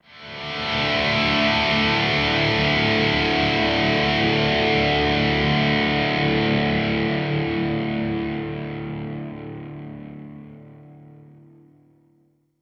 guitar.wav